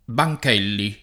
[ ba j k $ lli ]